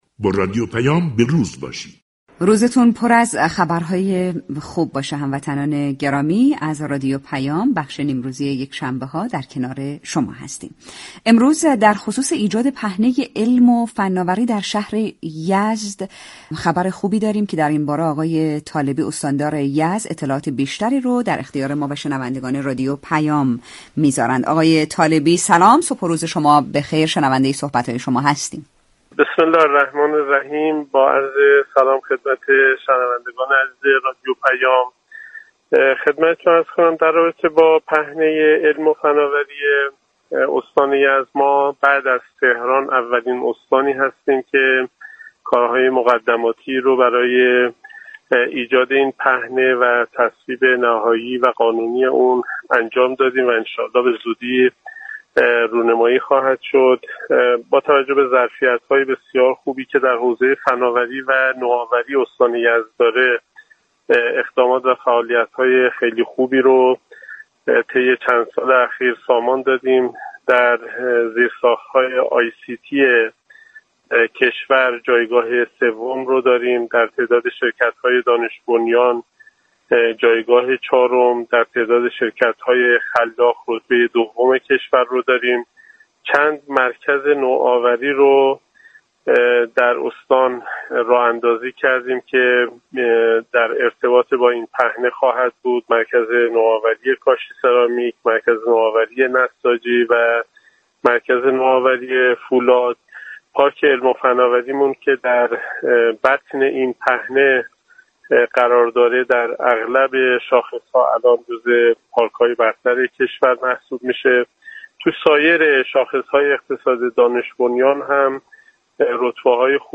طالبی ، استاندار یزد ، در گفتگو با رادیو پیام ، دستآوردهای متخصصان استان یزد در حوزه علم و فناوری (ICT) و اقدامات زیربنایی برای پیوستن استان به پهنه فضای الكترونیك را بازگو كرد .